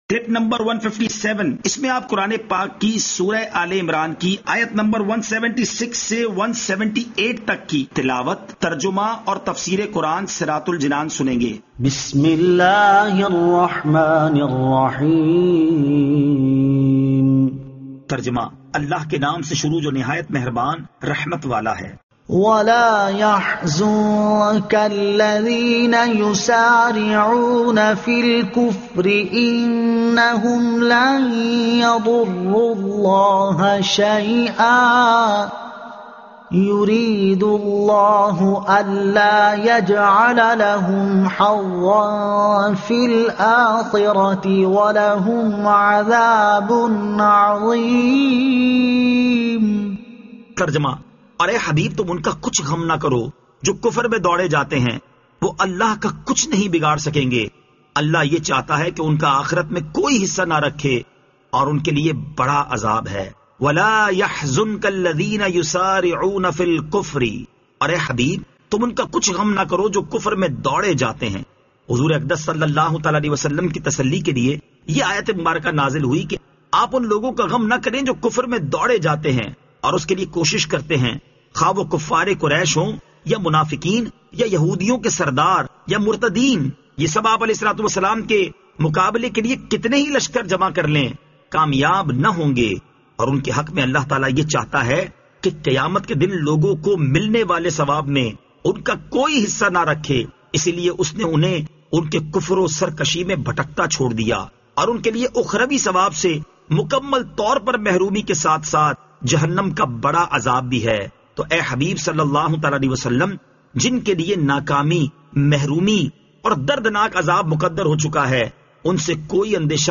Surah Aal-e-Imran Ayat 176 To 178 Tilawat , Tarjuma , Tafseer